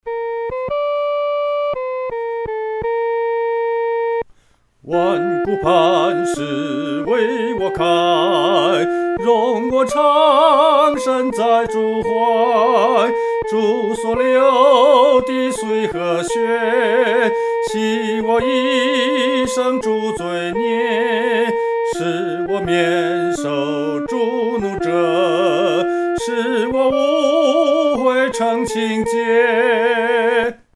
独唱（第一声）
万古磐石-独唱（第一声）.mp3